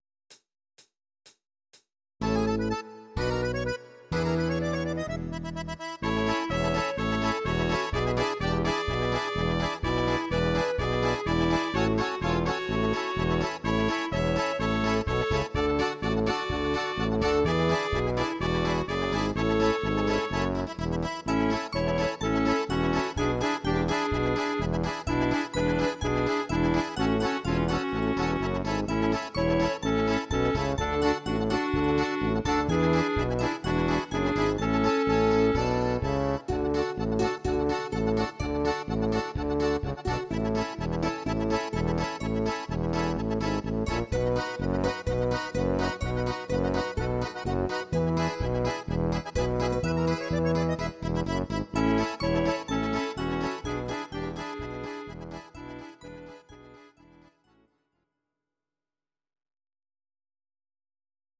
Audio Recordings based on Midi-files
Instrumental, Traditional/Folk, Volkstï¿½mlich